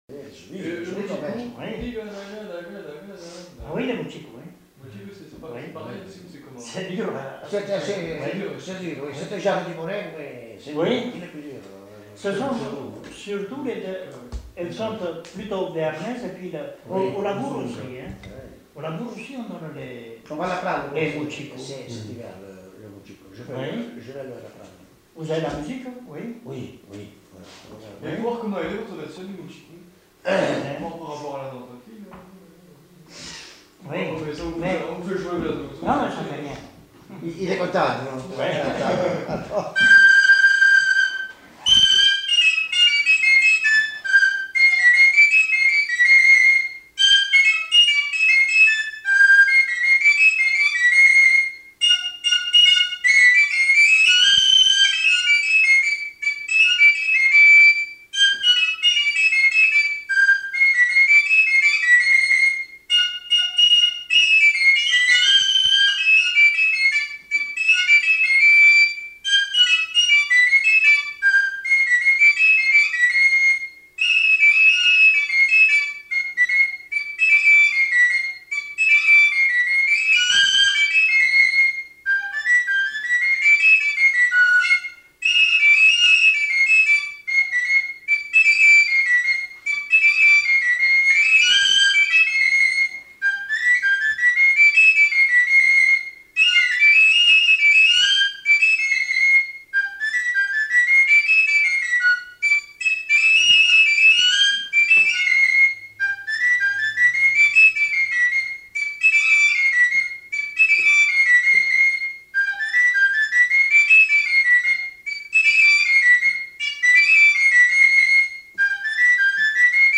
Aire culturelle : Pays Basque
Lieu : Trois-Villes
Genre : morceau instrumental
Instrument de musique : txistu
Danse : mochico